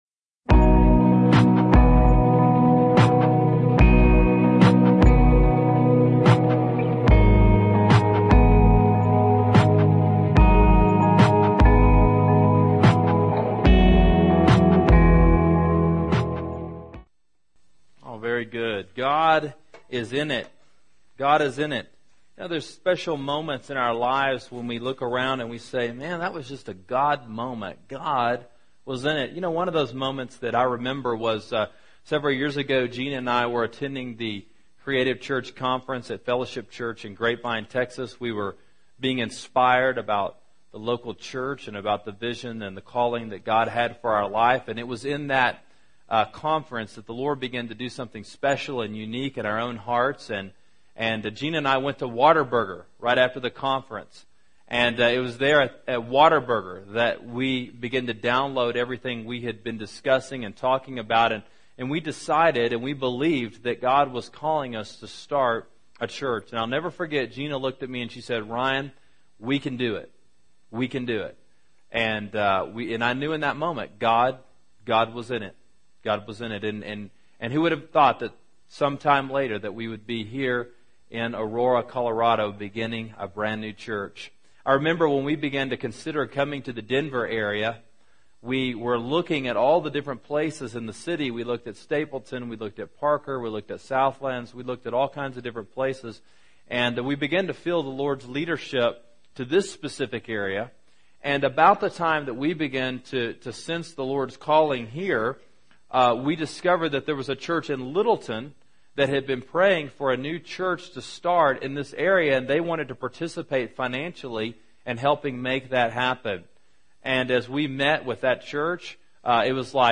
Radical Generosity: God Is In It – John 12:1-8 – Sermon Sidekick